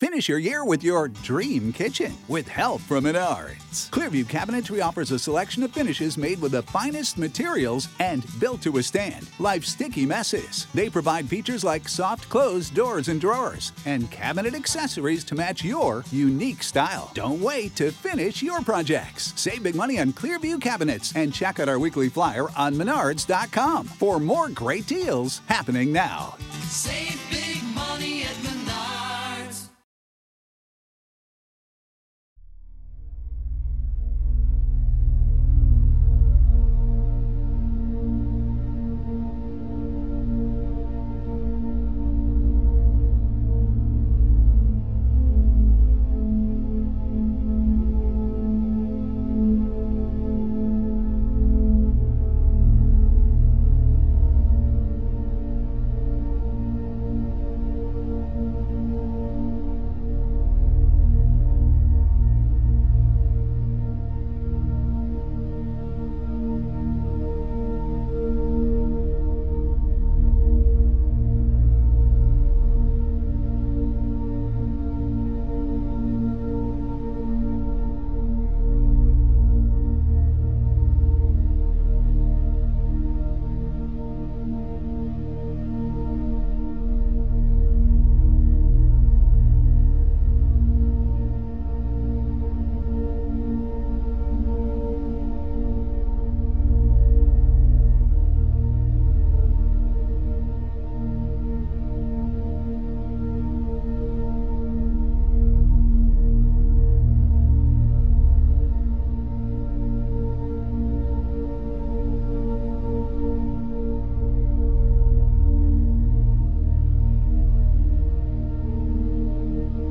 432 Hz is the frequency of balance and harmony.
Headphones recommended for full immersion.
Frequency Focus: 432 Hz – The natural tuning of the cosmos.